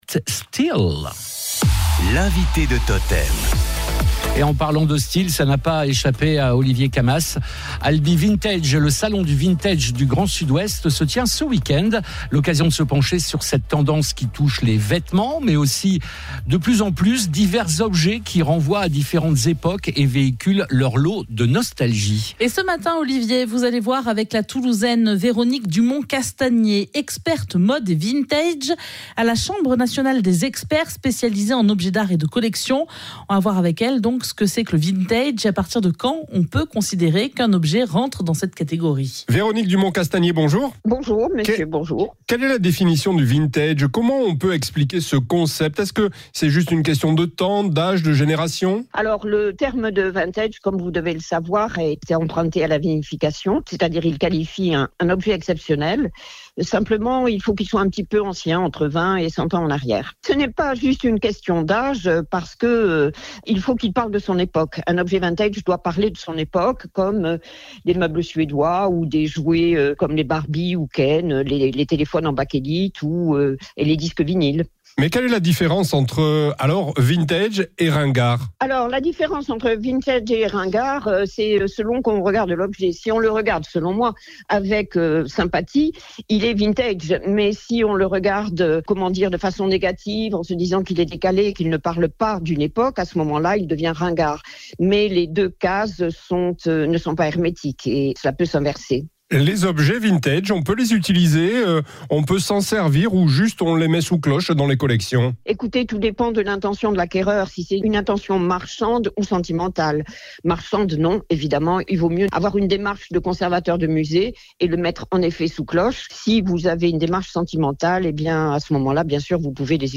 Un point sur le marché vintage en trois petites minutes, sur radio totem